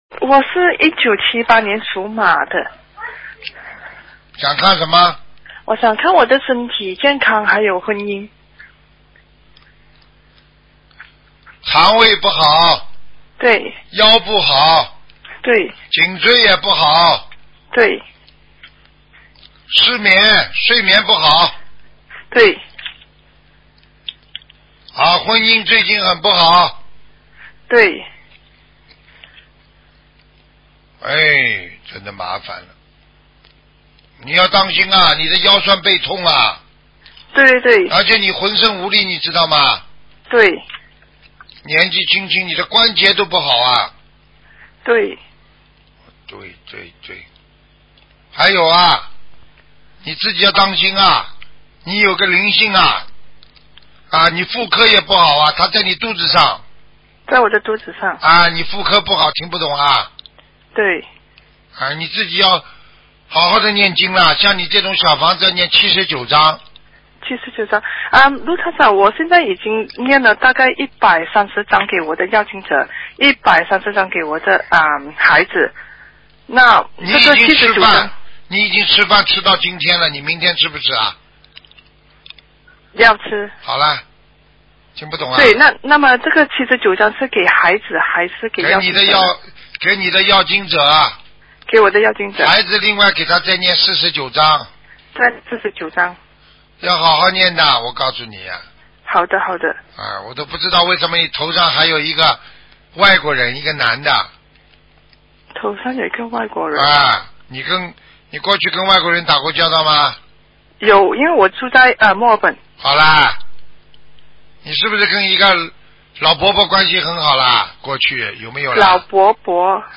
目录：2016年剪辑电台节目录音_集锦